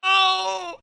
Screaming Dedman 3